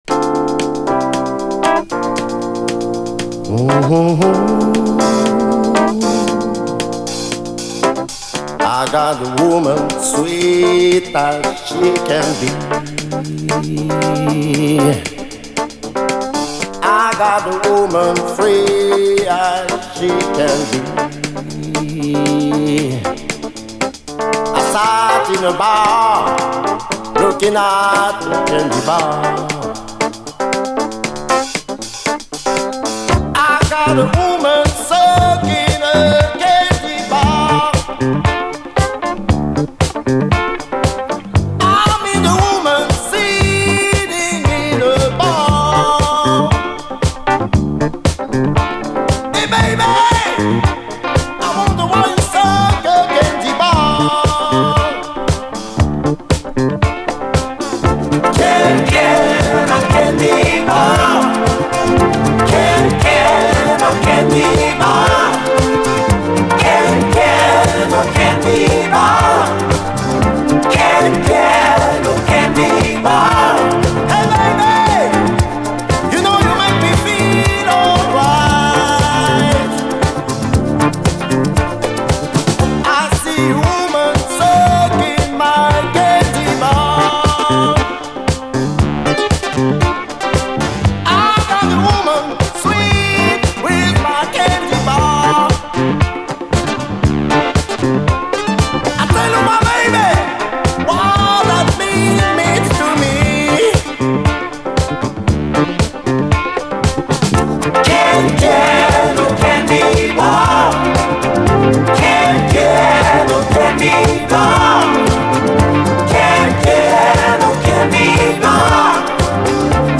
DISCO
妖しさ満点のフレンチ・アーバン・シンセ・ブギー！悩ましく広がるシンセ・サウンド！